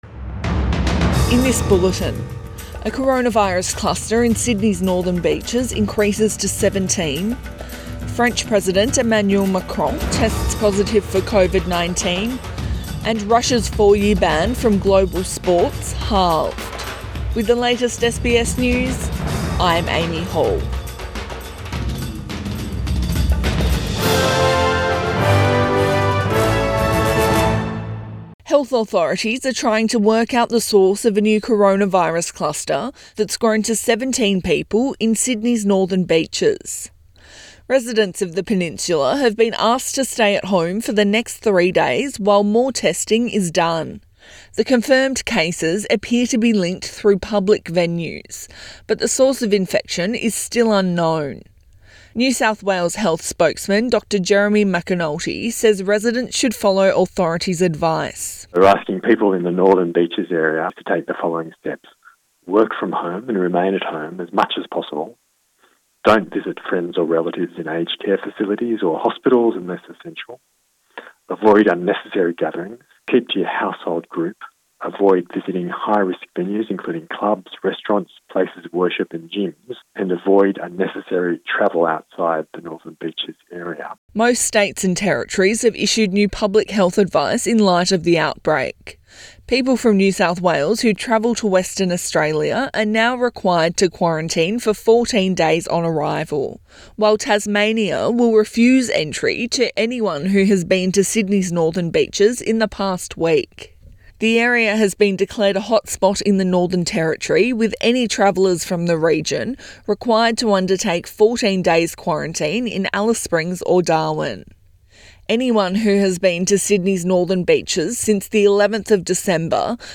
AM bulletin 18 December 2020